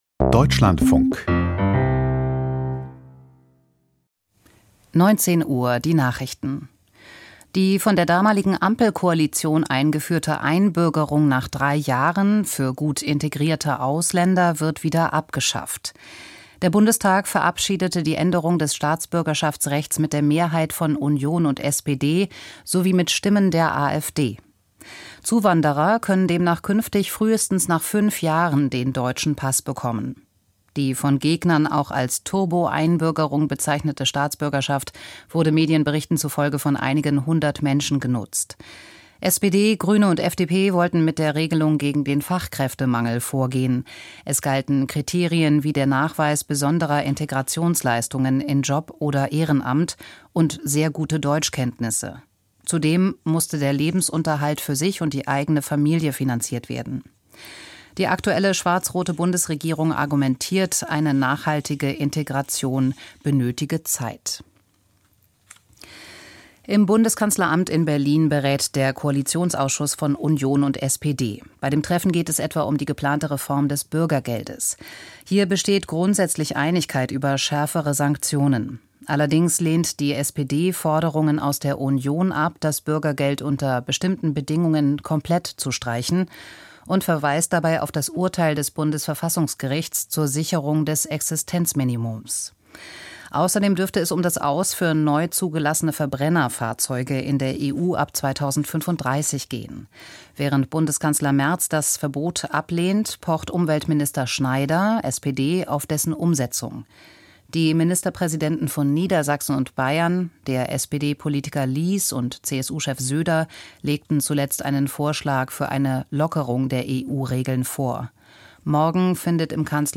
Die Nachrichten vom 08.10.2025, 19:00 Uhr